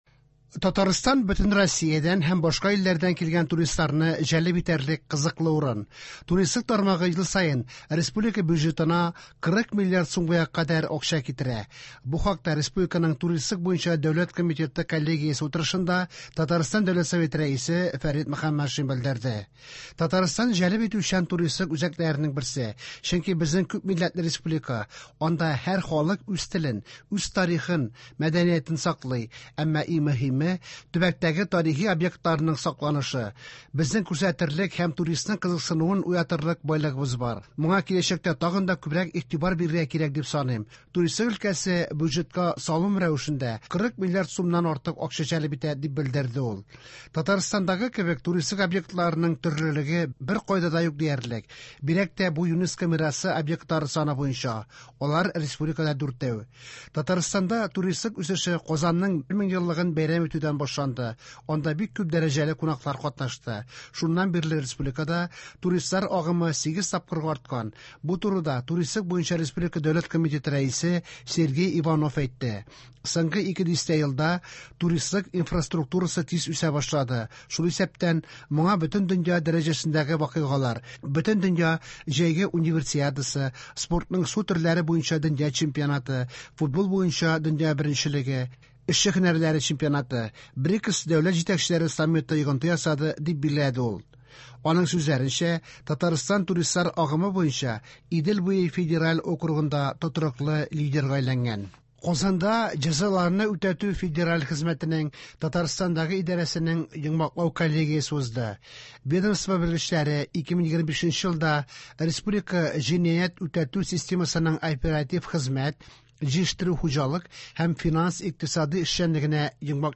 Яңалыклар (22.01.26)
Иртәнге чыгарылыш